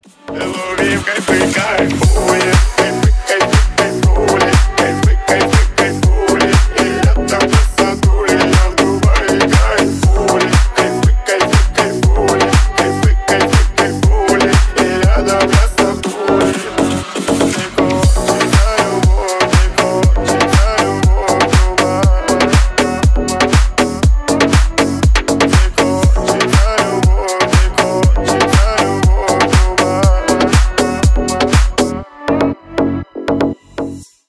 Ремикс # Рэп и Хип Хоп
клубные